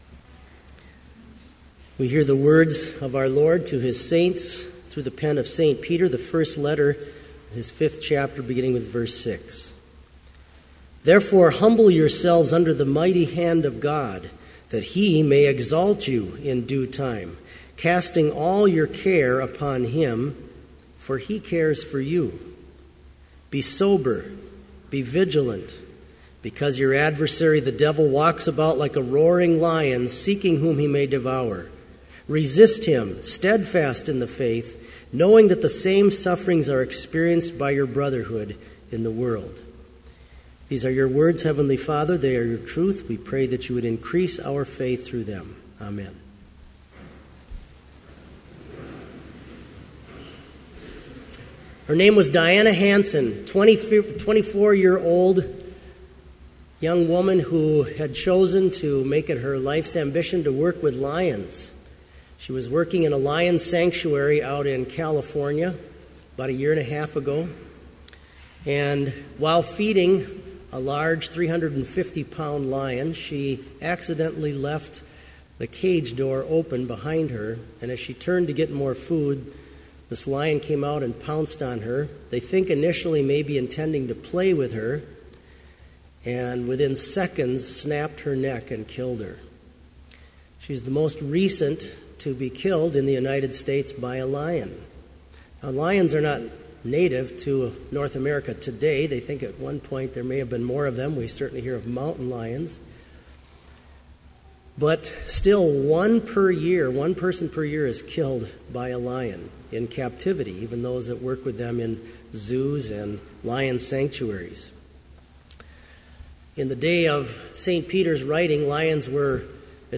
Complete service audio for Chapel - February 24, 2015